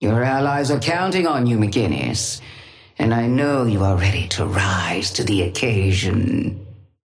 Sapphire Flame voice line - Your allies are counting on you, McGinnis. And I know you are ready to rise to the occasion.
Patron_female_ally_forge_start_04.mp3